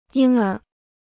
婴儿 (yīng ér): infant; baby.